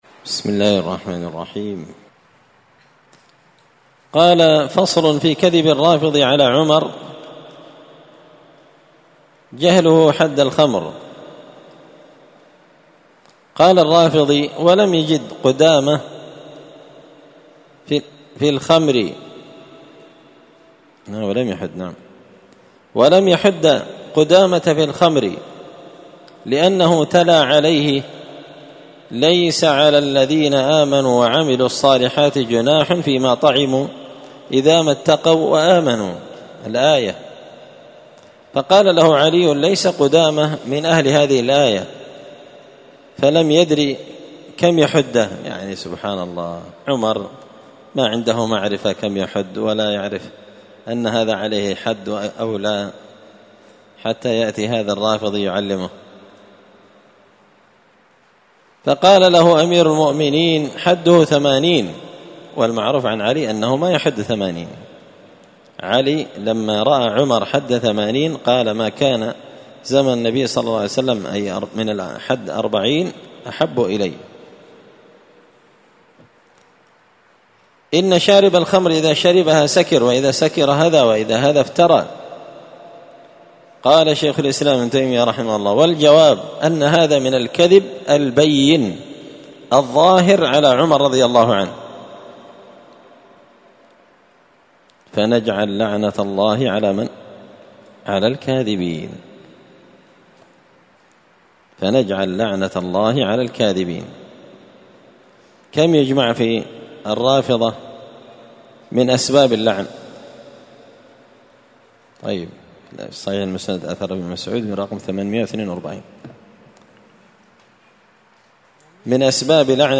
الأربعاء 3 ذو الحجة 1444 هــــ | الدروس، دروس الردود، مختصر منهاج السنة النبوية لشيخ الإسلام ابن تيمية | شارك بتعليقك | 6 المشاهدات
مسجد الفرقان قشن_المهرة_اليمن